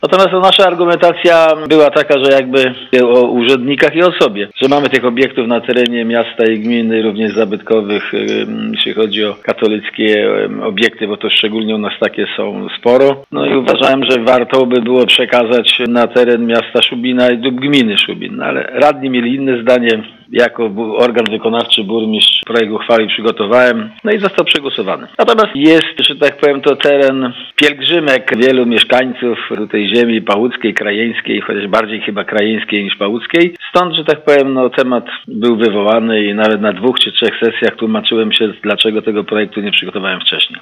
Mówił ponownie burmistrz Ignacy Pogodziński.